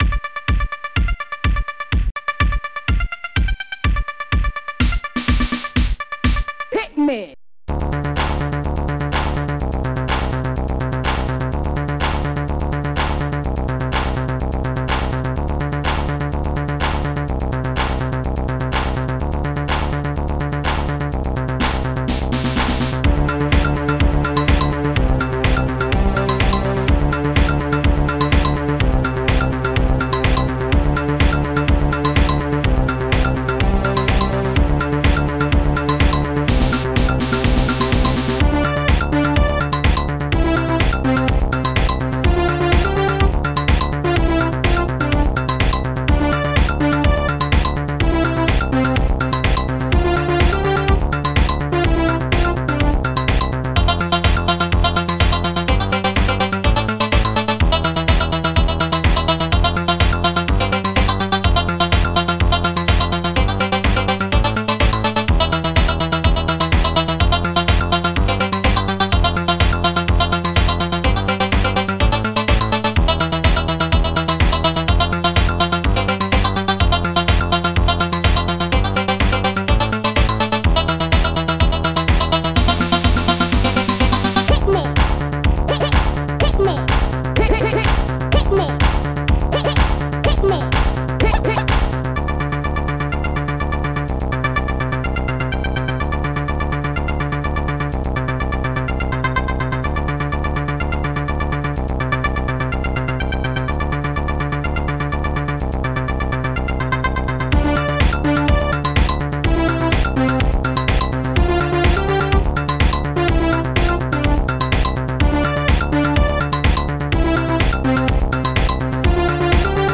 st-04:bassdrum1
st-04:SNAREDRUM7
st-05:hihat
st-02:SoloBass
st-04:claps1
st-04:bell2
st-01:synbrass
st-04:woodblock
st-01:Strings4